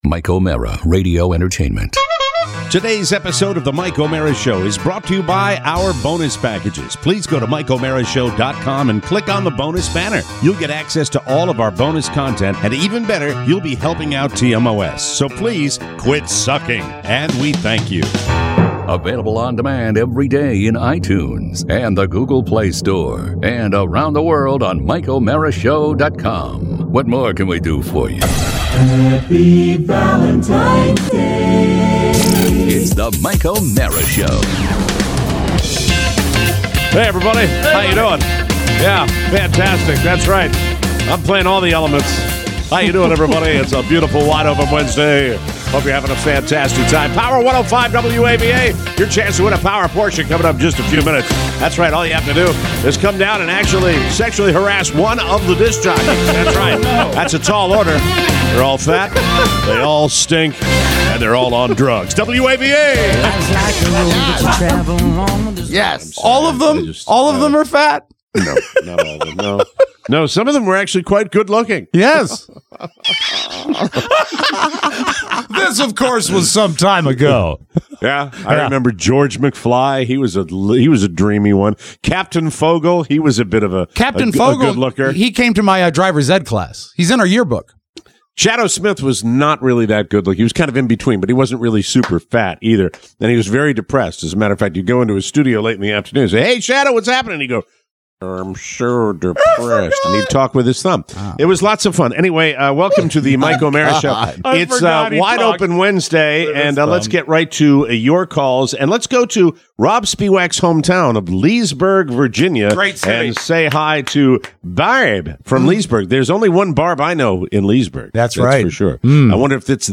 A legendary, listener packed Wide Open Wednesday! Old regulars… a lecture… stock analysis.